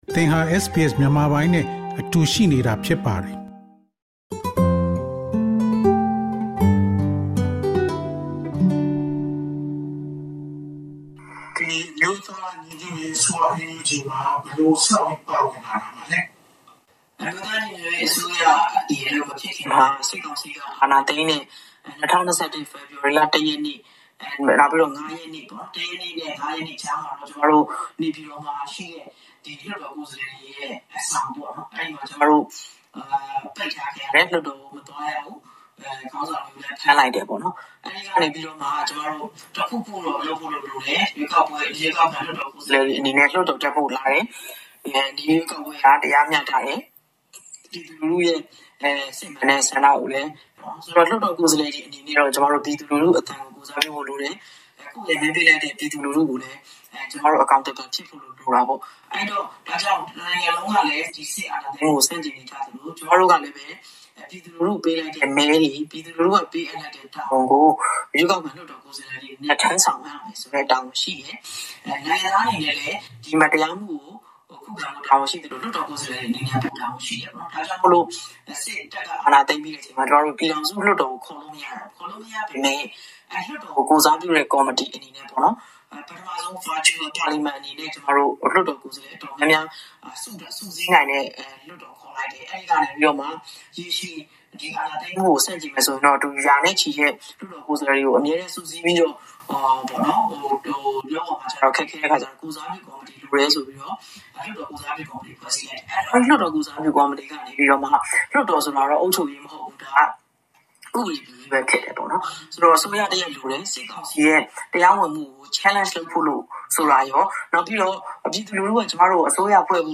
NUG နိုင်ငံခြားရေးဝန်ကြီး ဒေါ်ဇင်မာအောင် တွေ့ဆုံမေးမြန်းချက် အပိုင်း ၂